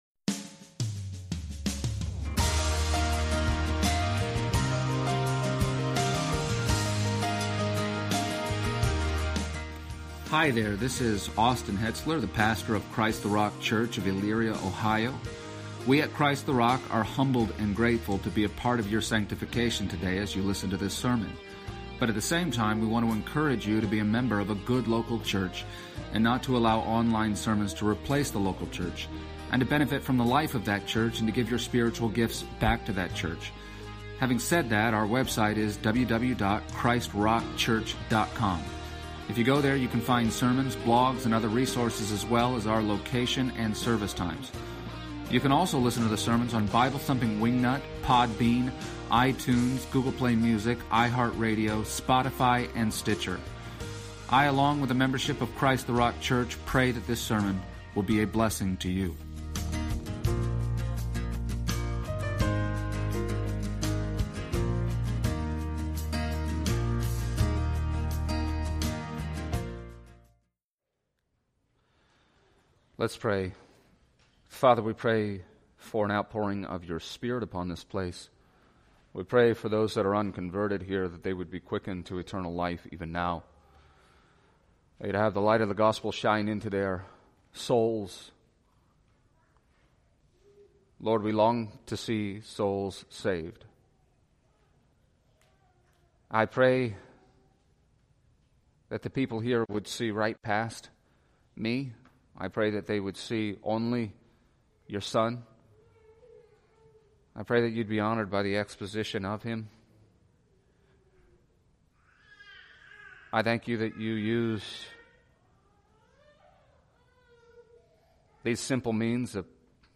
Exposition of the Gospel of John Passage: John 19:17-30 Service Type: Sunday Morning %todo_render% « Behold The Man It Is Finished